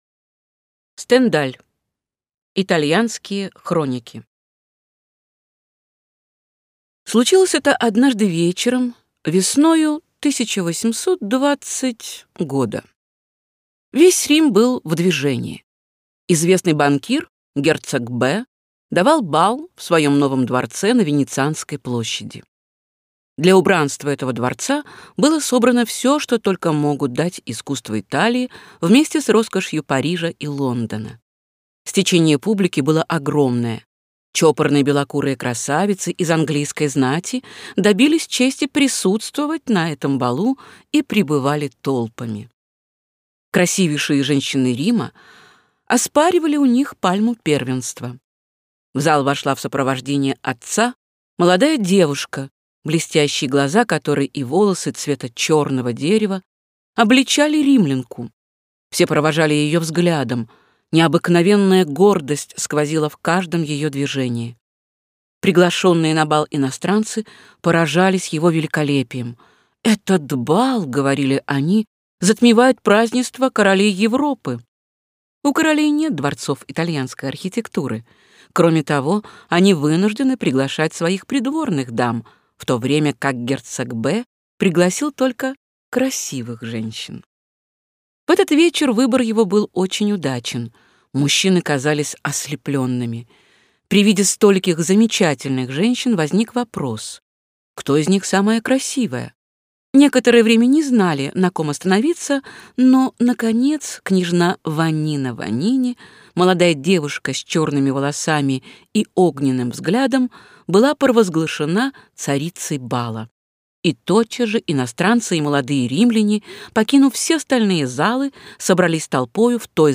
Аудиокнига Итальянские хроники | Библиотека аудиокниг